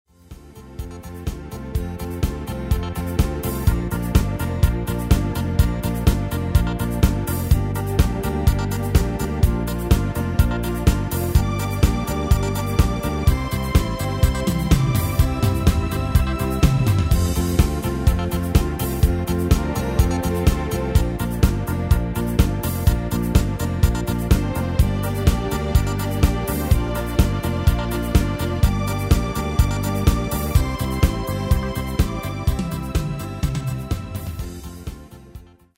Medley Extended MIDI File Euro 14.50